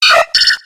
Grito de Togepi.ogg
Grito_de_Togepi.ogg